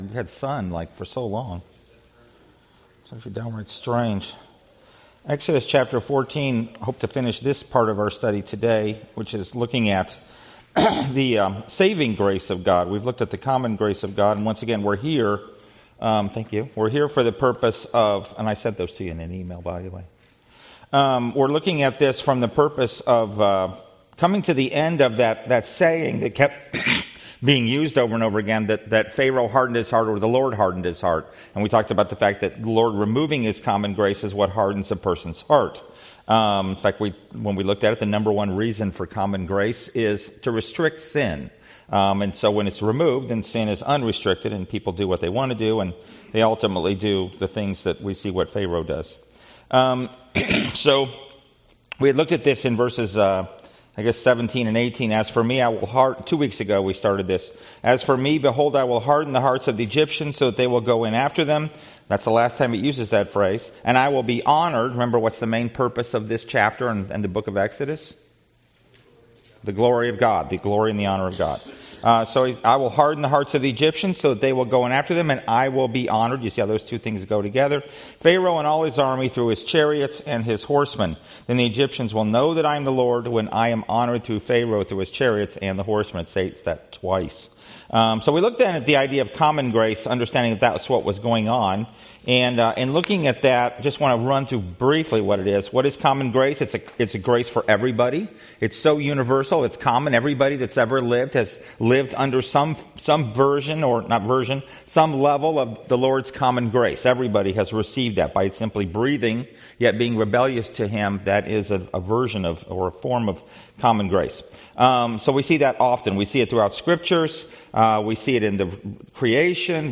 Passage: Exodus 14 Service Type: Sunday School « Sorrento